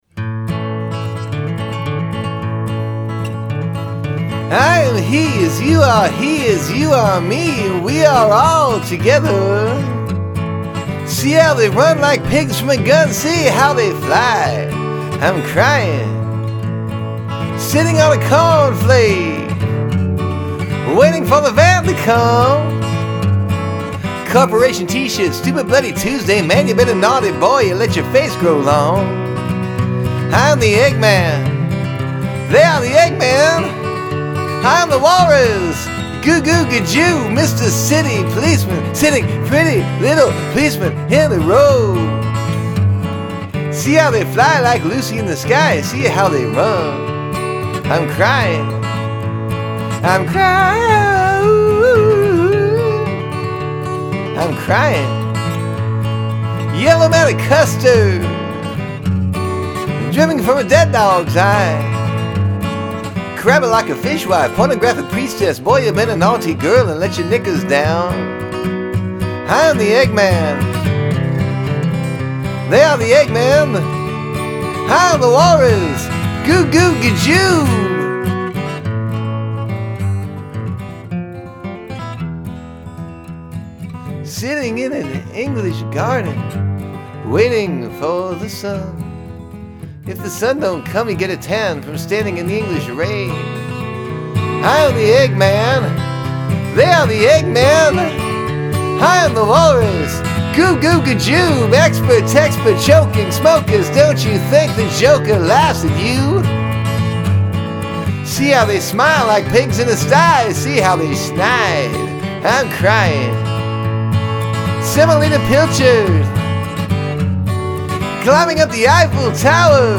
Dylan-esque